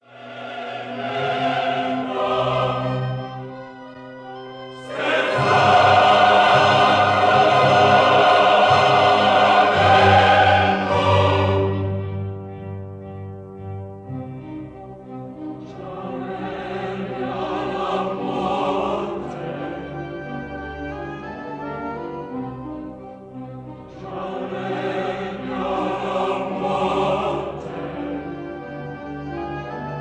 tenor
and Orchestra
Recorded in Abbey Road Studio No. 1, London